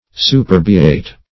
Search Result for " superbiate" : The Collaborative International Dictionary of English v.0.48: Superbiate \Su*per"bi*ate\, v. t. [Cf. L. superbiare.] To make (a person) haughty.